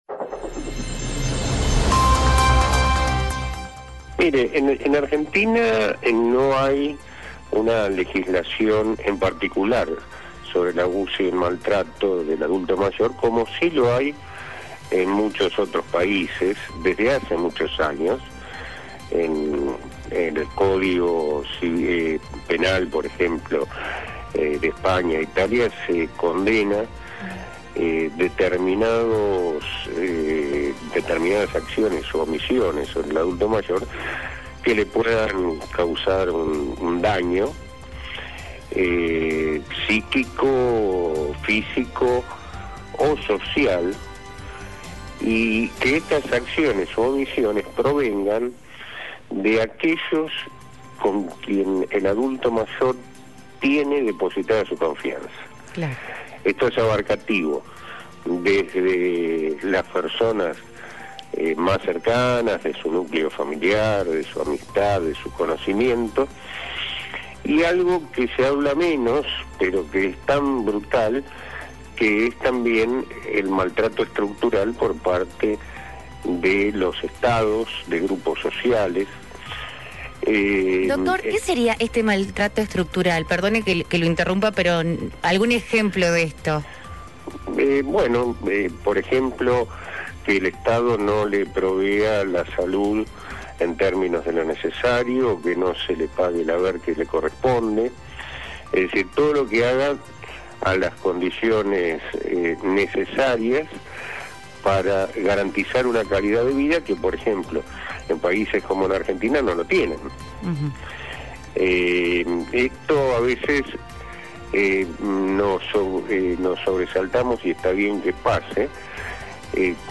En el podcast de hoy hablamos con el Doctor Eugenio Semino, Defensor de la Tercera Edad, Gerontólogo, Abogado (egresado de la UBA), Criminólogo, Docente en Gerontología (Instituto Nacional de Migraciones y Servicios Sociales de España) entre otras cosas para conocer la realidad de los abusos que viven los adultos mayores en la Argentina y el mundo entero.